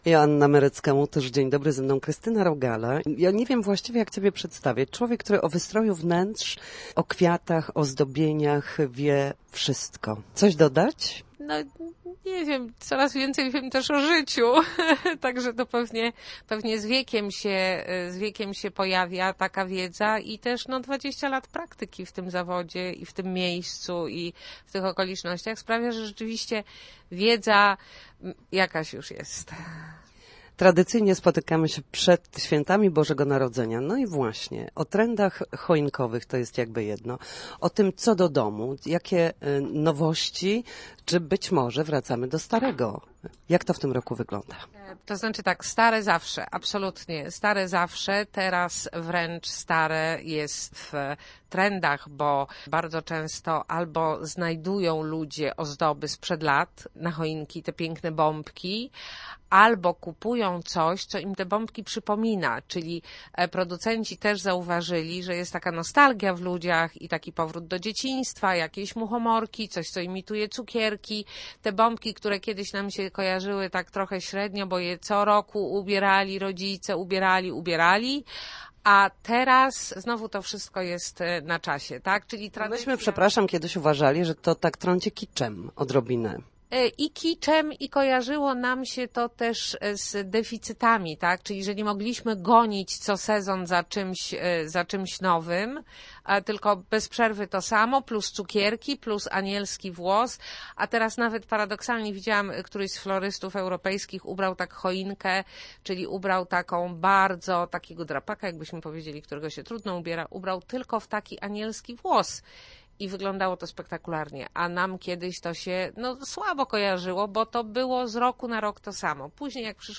Najnowsze trendy na święta. Posłuchaj rozmowy z dekoratorką i florystką